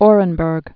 (ôrən-bûrg, ə-rĭn-brk)